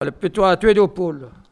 Olonne-sur-Mer
locutions vernaculaires